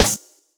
dsh_snr.wav